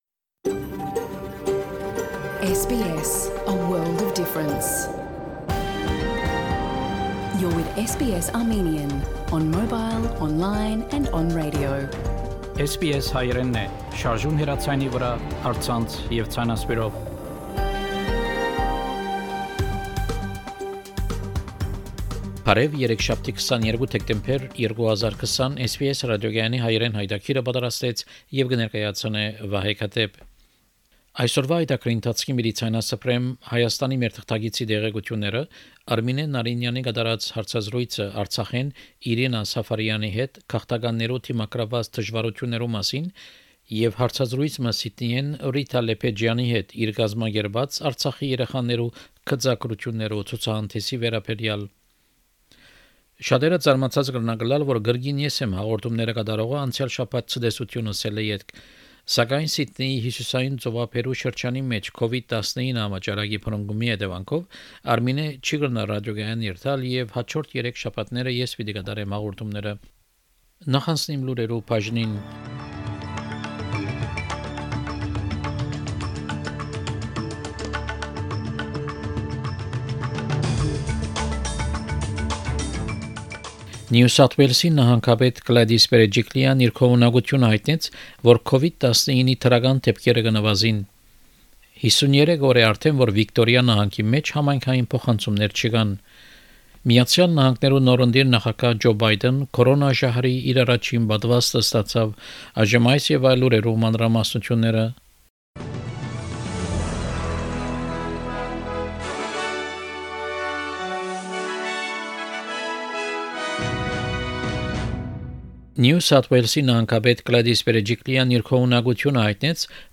SBS Armenian news bulletin – 22 December 2020
SBS Armenian news bulletin from 22 December 2020 program.